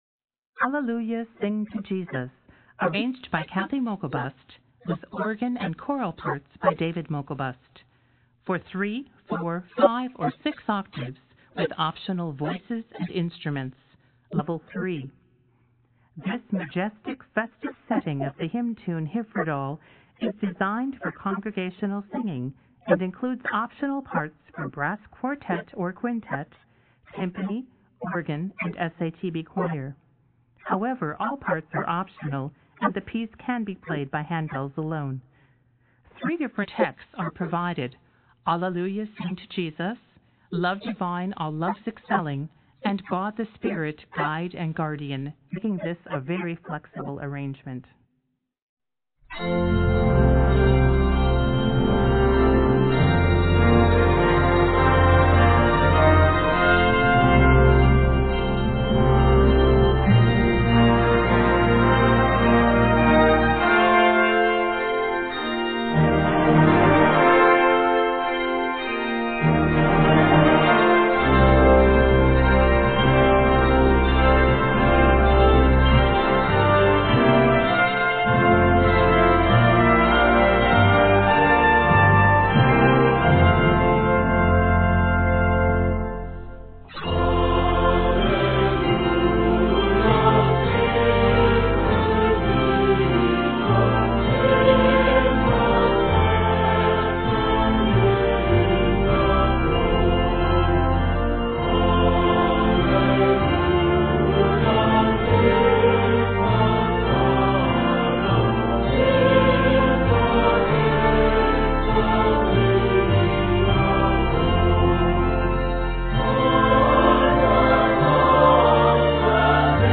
This majestic, festive concertato
This piece is 168 measures and scored in F Major.